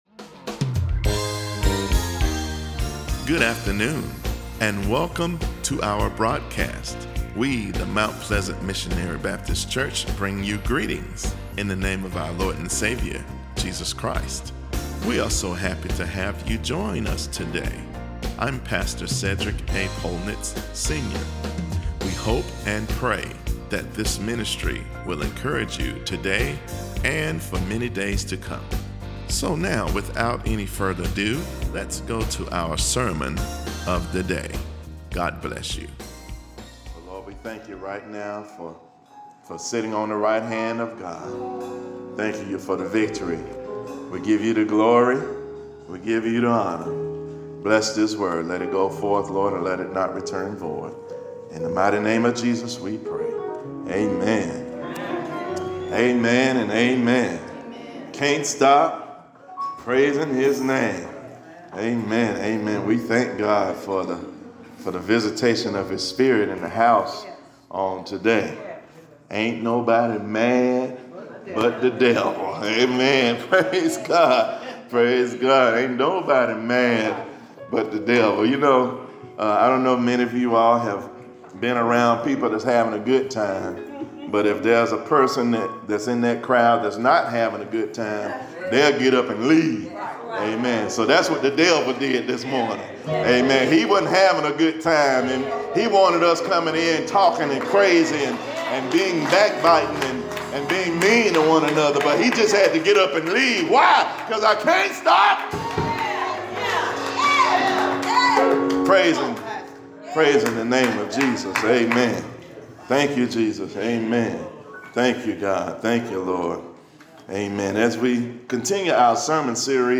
Closing Song: God has been Good